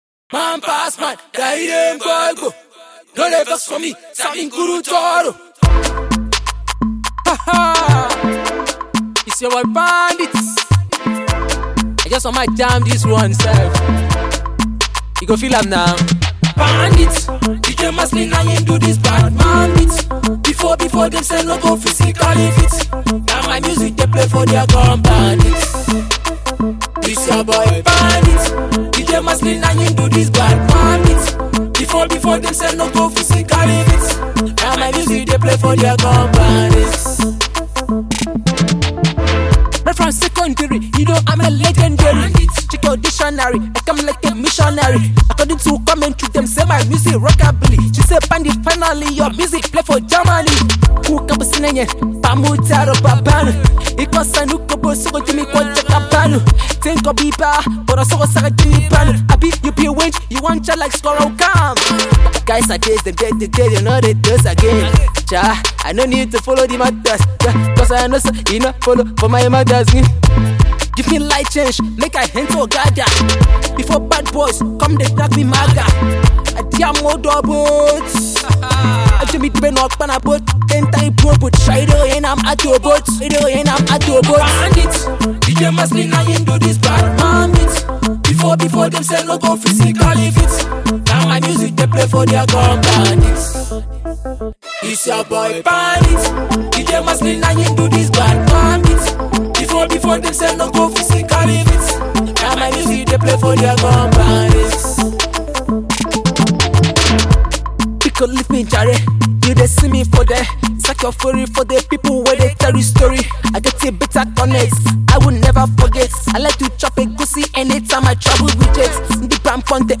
indigenous rap